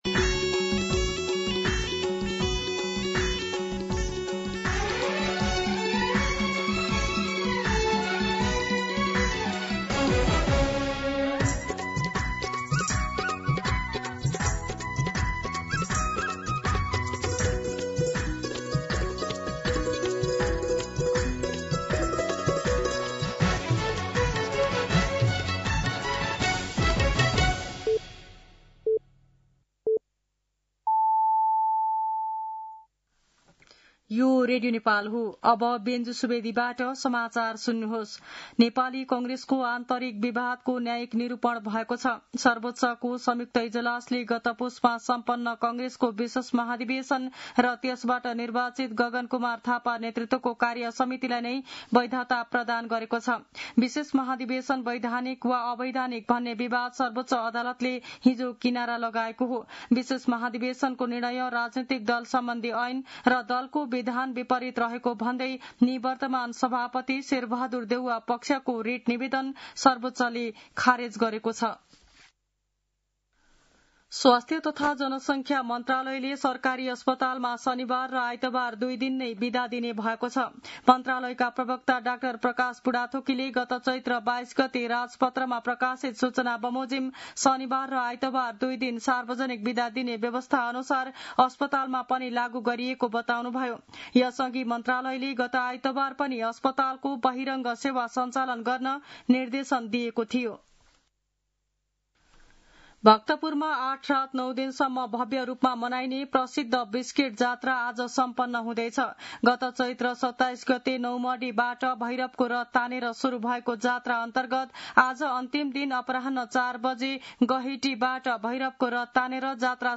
मध्यान्ह १२ बजेको नेपाली समाचार : ५ वैशाख , २०८३
12-pm-Nepali-News-1.mp3